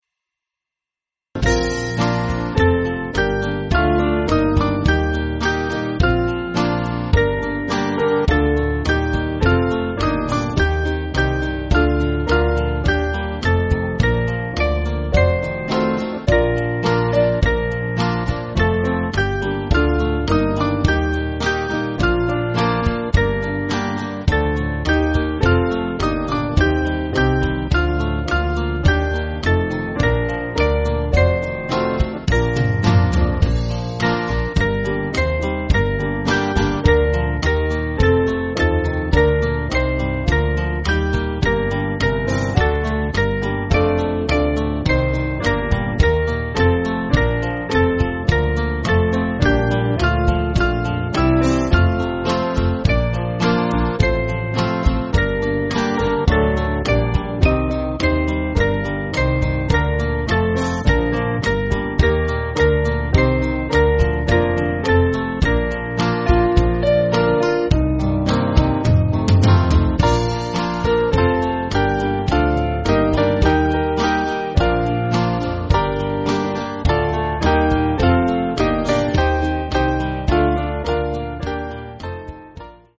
Small Band
(CM)   3/Bb